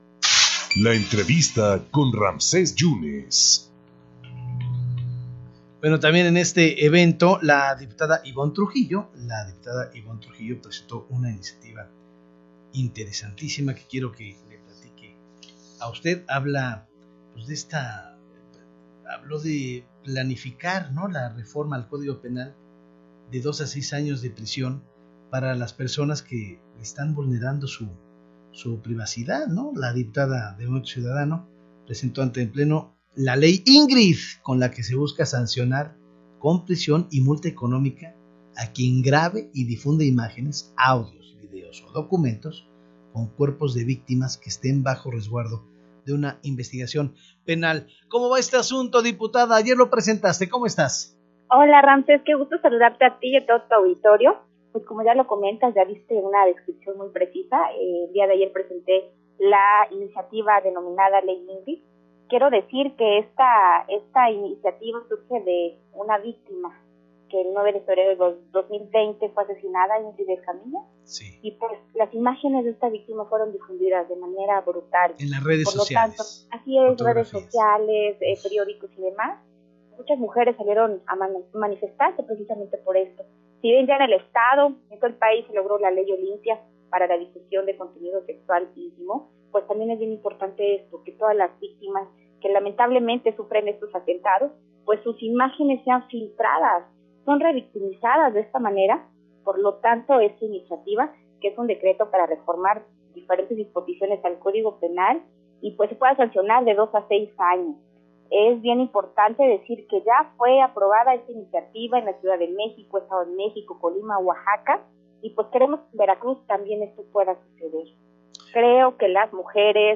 Redacción/Xalapa.- La diputada Ivonne Trujillo platicó para En Contacto sobre la nueva reforma al código penal que ha impulsado para sancionar con cárcel a quienes difundan imágenes o vídeos de personas que han sido víctimas de algún delito.